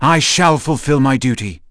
Zafir-Vox_Skill6.wav